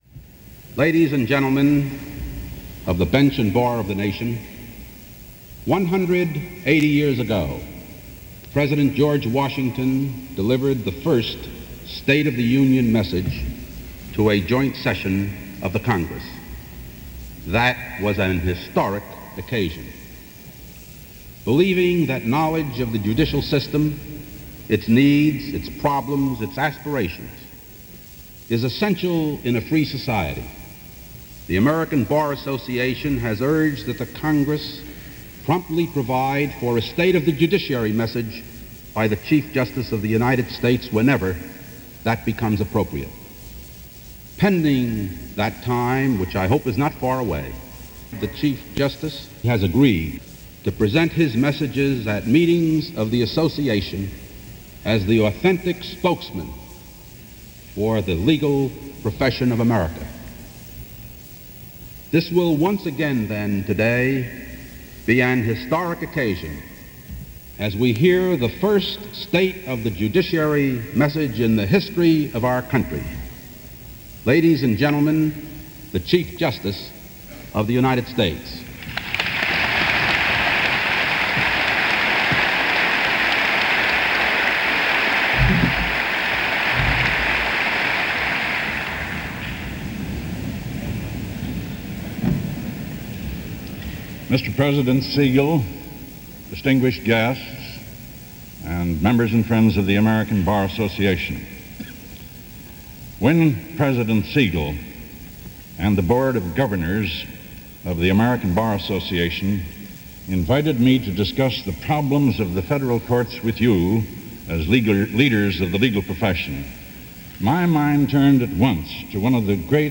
Chief Justice Warren Burber - The State Of The Judiciary - 1970 - Address given on August 10, 1970 - Past Daily After Hours Reference Room.